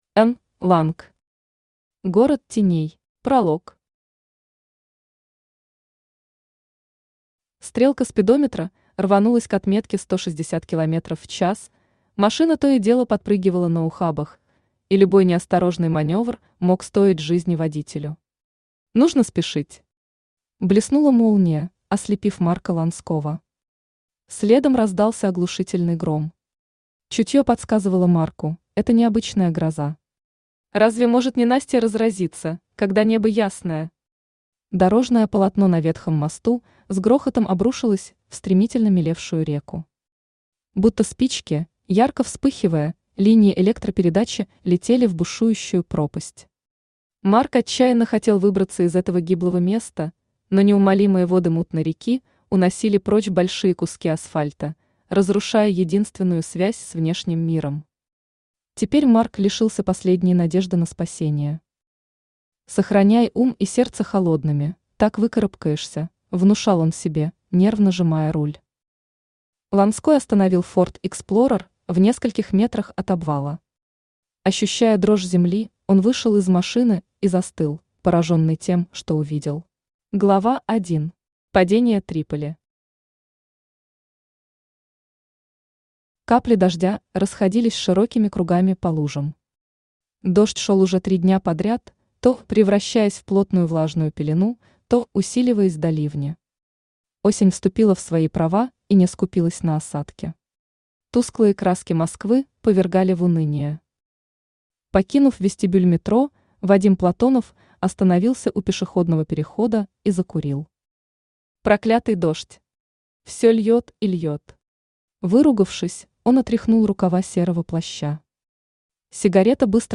Аудиокнига Город теней | Библиотека аудиокниг
Aудиокнига Город теней Автор Н. Ланг Читает аудиокнигу Авточтец ЛитРес.